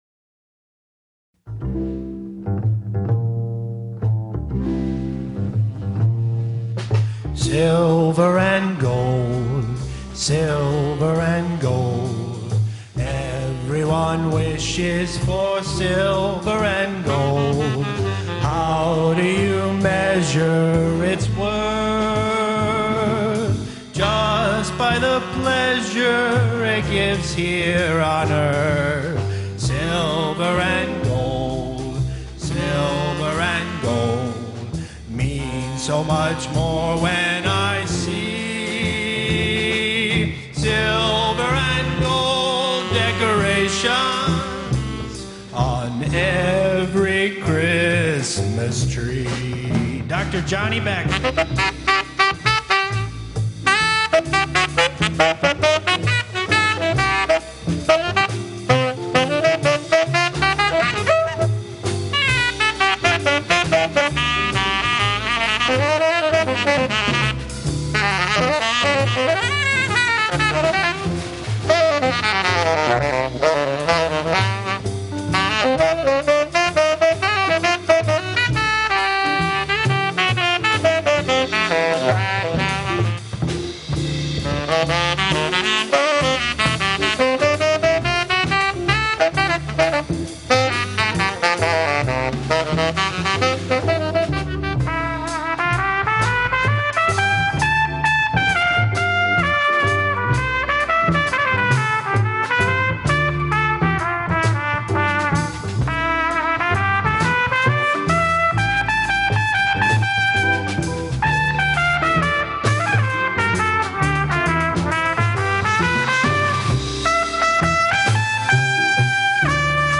jazz, classical, rock